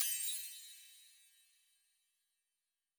Magic Chimes 04.wav